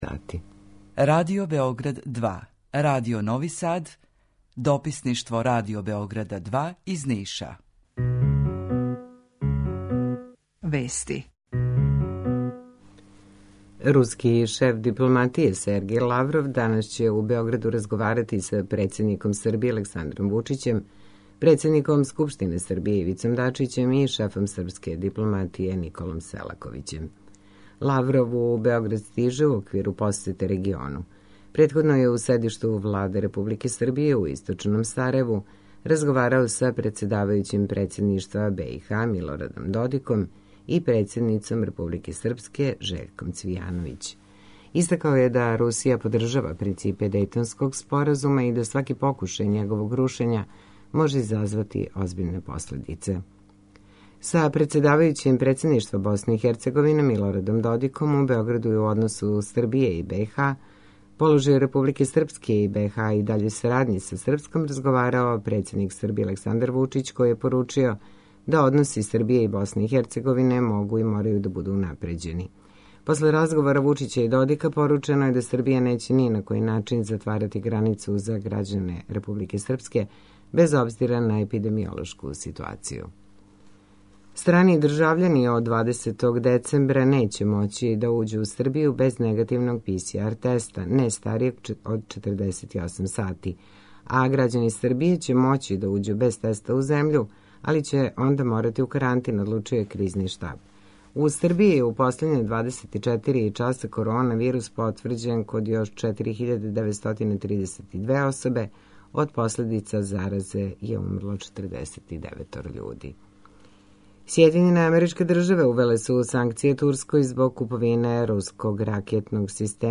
Јутарњи програм заједнички реализују Радио Београд 2, Радио Нови Сад и дописништво Радио Београда из Ниша. Cлушаоци могу да чују најновије информације из сва три града, најаве културних догађаја, теме које су занимљиве нашим суграђанима без обзира у ком граду живе.
У два сата, ту је и добра музика, другачија у односу на остале радио-станице.